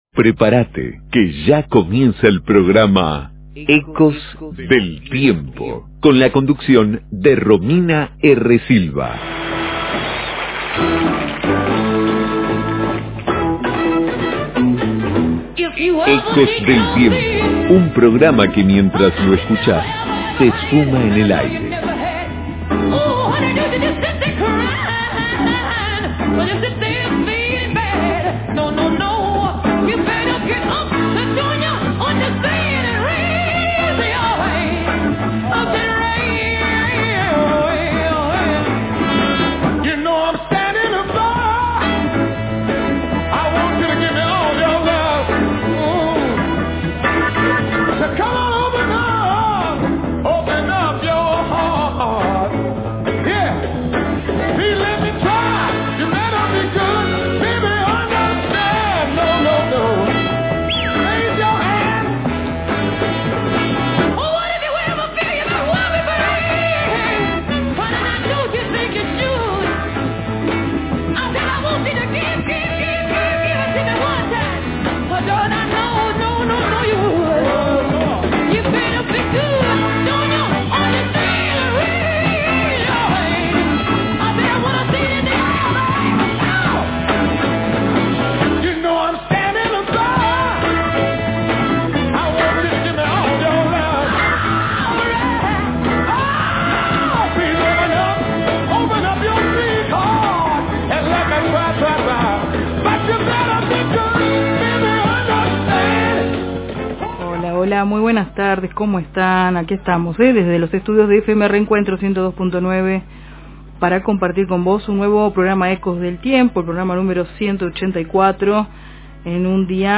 Efemérides generales🎶🎶🎶 🎙🙂 Charlamos en vivo